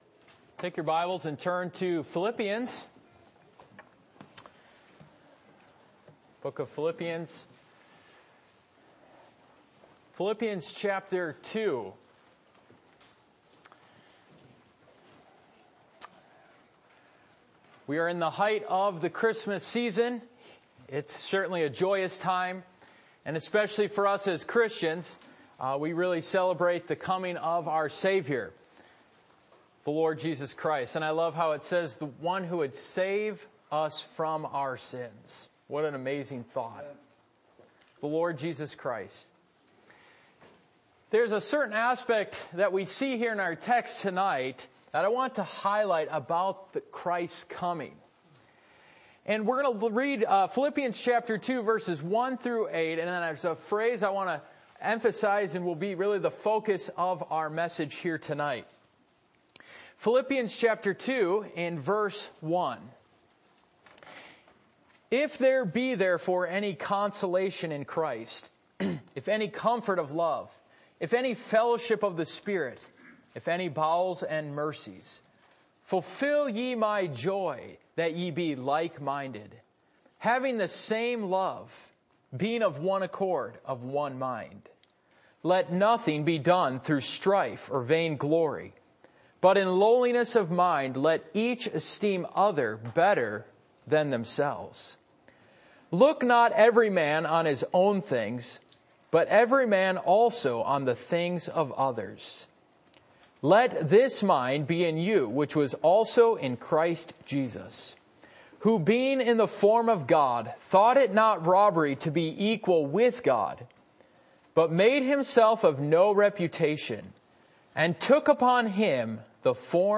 Passage: Philippians 2:1-8 Service Type: Midweek Meeting %todo_render% « A Faith that is Blessed by God What Voice Do You Hear?